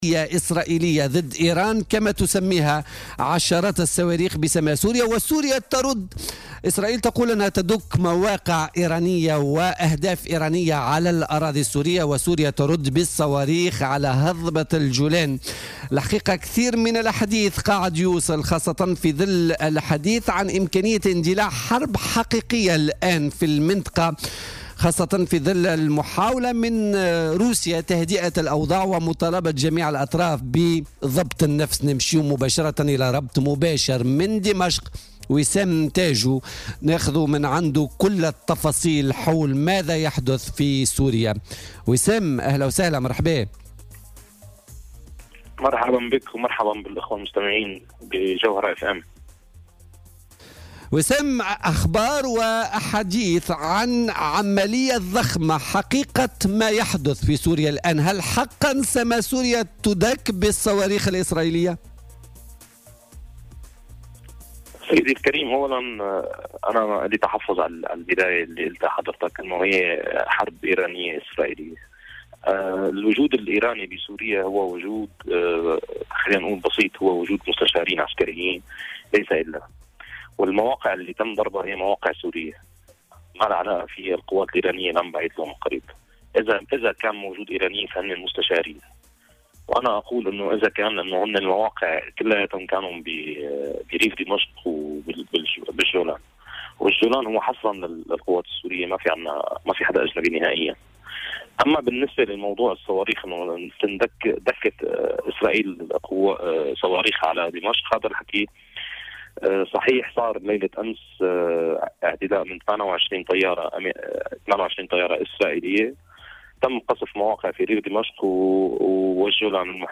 مداخلة هاتفية